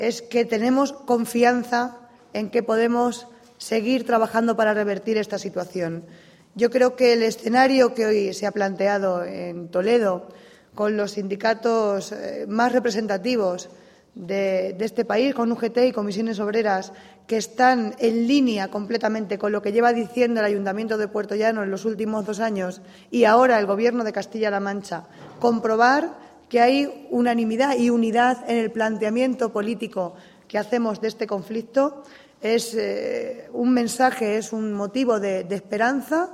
Presidente Lunes, 31 Agosto 2015 - 9:45pm Declaraciones de la alcaldesa de Puertollano, Mayte Fernández, tras el encuentro mantenido junto al presidente de Castilla-La Mancha, Emiliano García-Page, en el Palacio de Fuensalida, con representantes de la Federación Estatal de Industria de los sindicatos CCOO y FITAG-UGT en torno a la situación actual de la central térmica de Elcogás y sus trabajadores. mayte_fernandez_elcogas.mp3